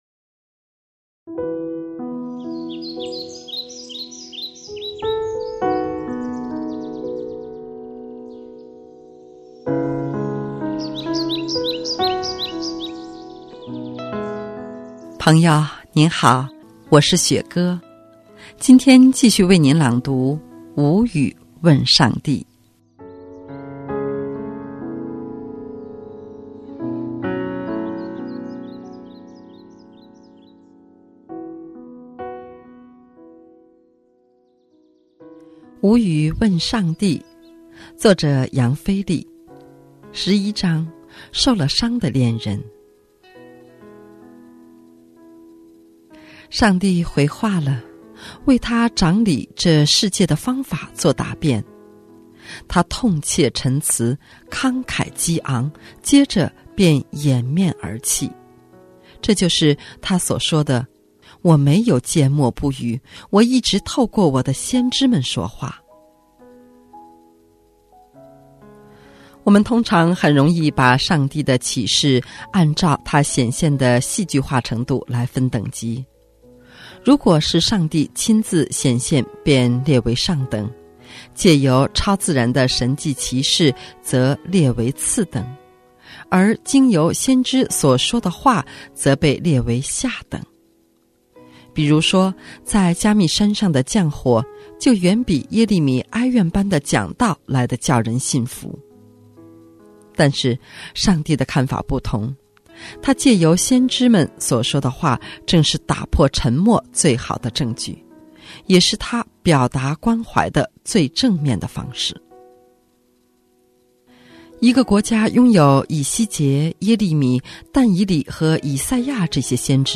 今天继续为您朗读《无语问上帝》。